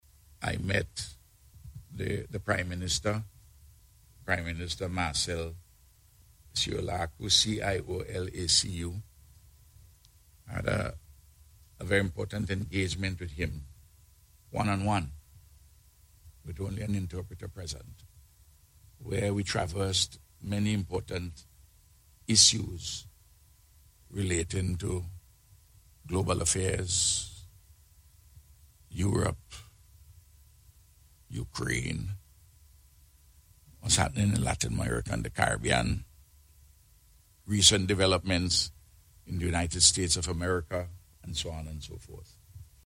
Prime Minister, Dr. Ralph Gonsalves, speaking at a Media Conference this morning.